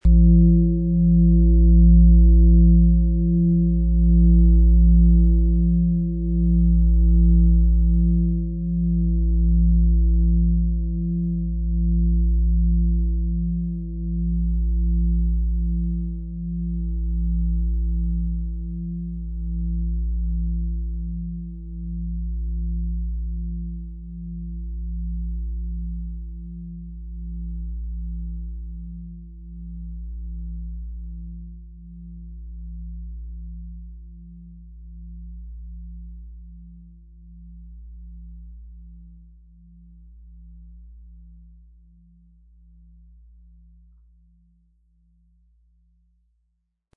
Planetenton 1
Ihre tiefen, ruhigen Schwingungen schaffen Raum für neue Perspektiven, geistige Ordnung und sanften Neubeginn.
Unter dem Artikel-Bild finden Sie den Original-Klang dieser Schale im Audio-Player - Jetzt reinhören.
Der richtige Schlegel ist umsonst dabei, er lässt die Klangschale voll und angenehm erklingen.